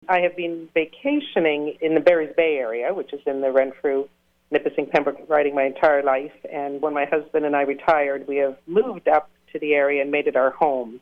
What you will be hearing is audio from an interview recorded May 22nd of 2022.